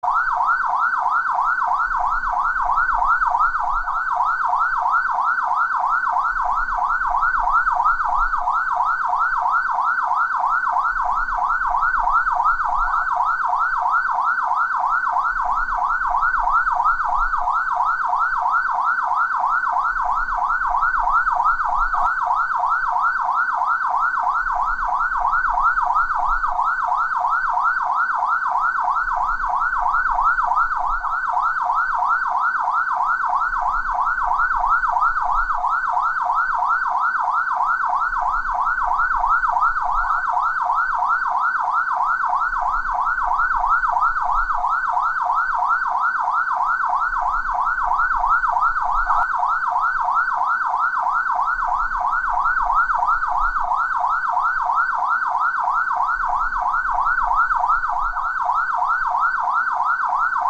ไซเรนตํารวจ ริงโทน mp3 ฟรี
ไซเรนตำรวจจากระยะไกลและใกล้ เสียงไซเรนรถตำรวจ mp3
หมวดหมู่: เสียงเรียกเข้า